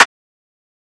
MZ Snare [Southside Bounce Lo].wav